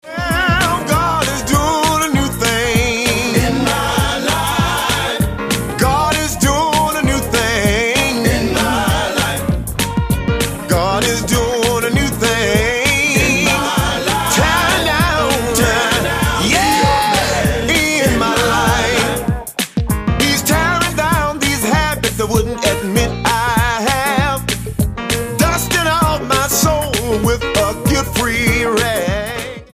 STYLE: Gospel
Sharp production gives it a strong sound